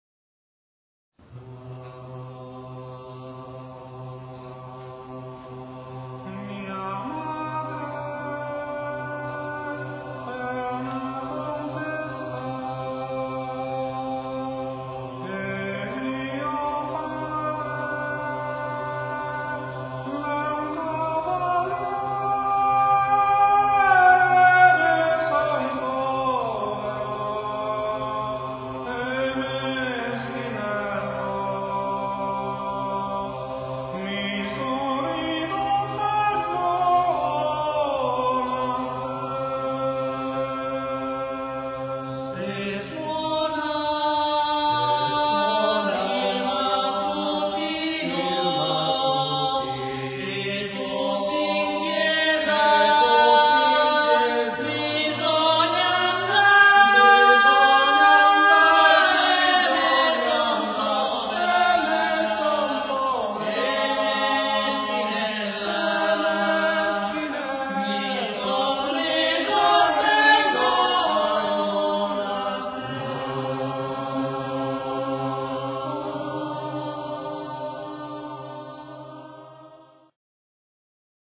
Ricerca, elaborazione, esecuzione di canti popolari emiliani